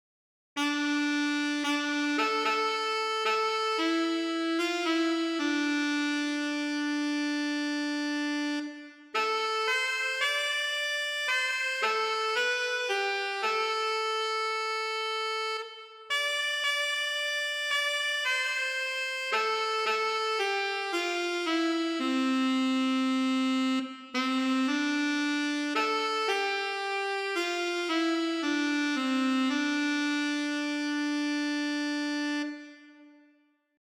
für Altsaxophon solo